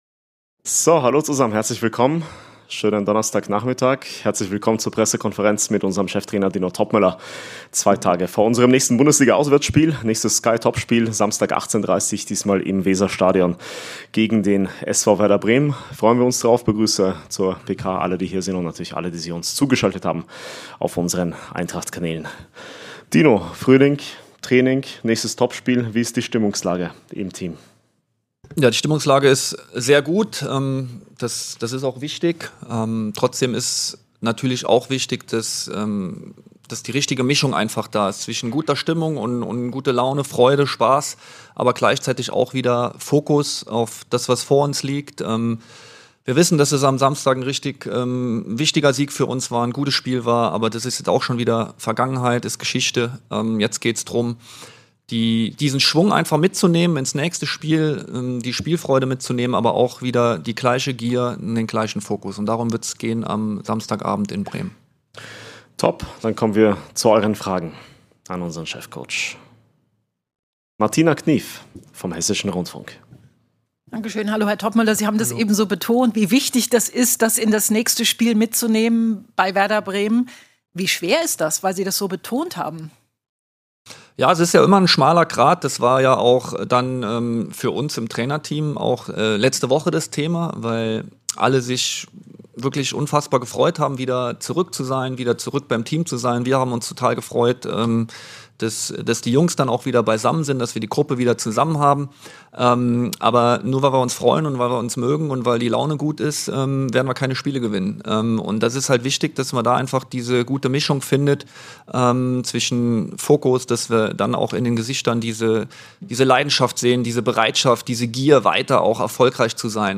Die Pressekonferenz mit Cheftrainer Dino Toppmöller vor dem Bundesliga-Auswärtsspiel beim SV Werder Bremen im Re-Live.